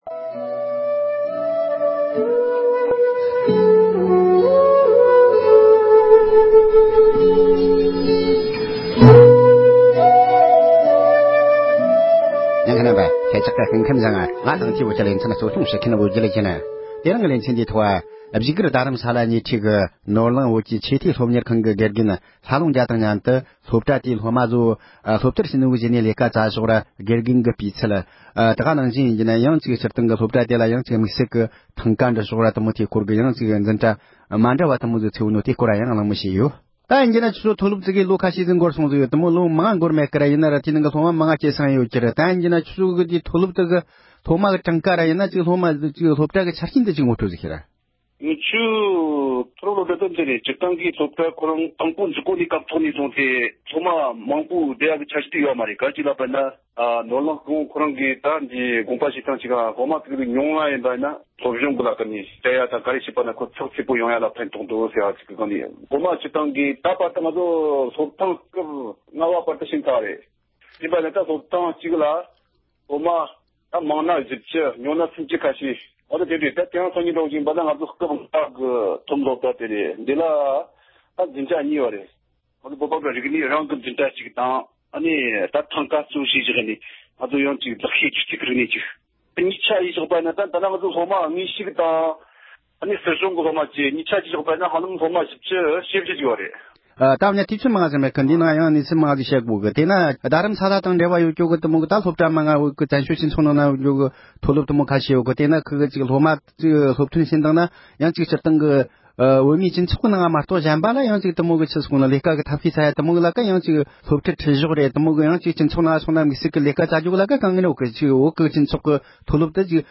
ནོར་གླིང་ཆེས་མཐོའི་མཐོ་སློབ་ཁང་གི་སློབ་མའི་མདུན་ལམ་དང་དགེ་རྒན་གྱི་སྤུས་ཚད་བཅས་ཀྱི་སྐོར་ལ་གླེང་མོལ།